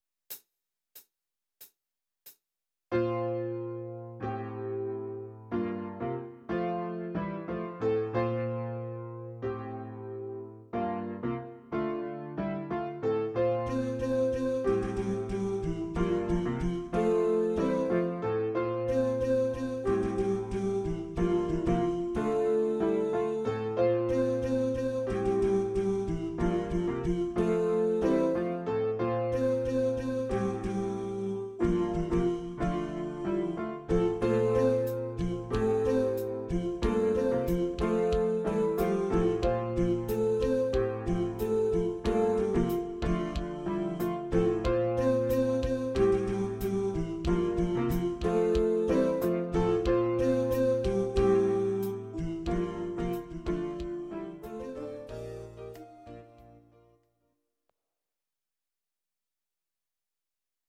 Audio Recordings based on Midi-files
Pop, Musical/Film/TV, 1990s